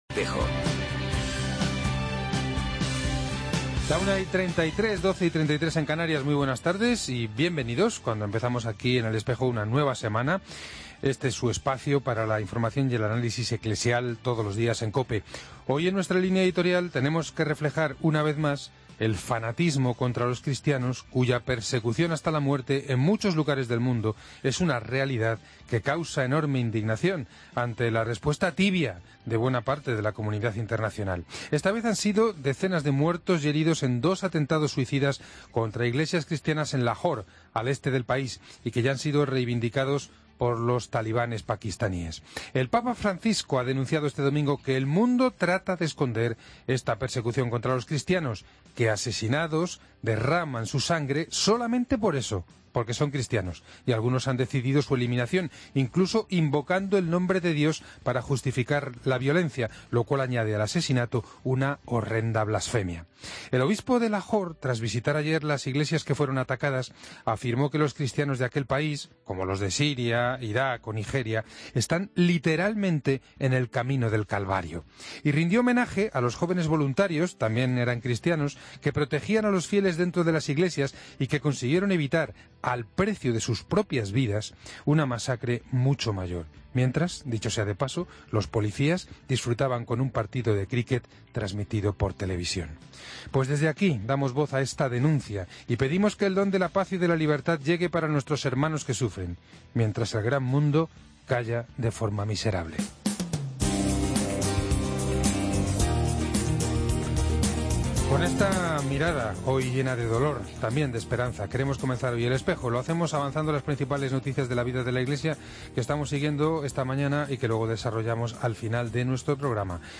Hoy en El Espejo hemos hablado con el Obispo de Coria-Cáceres, Mons. Francisco Cerro, que es además Presidente del Instituto Internacional del Corazón de Cristo, de cuya teología y espiritualidad hablaremos con él.